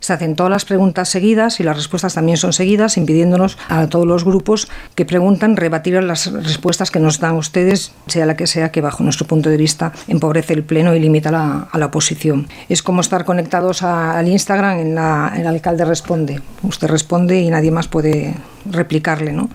Recuperem les seves intervencions durant la sessió d’abril:
Celine Coronile, portaveu del PP: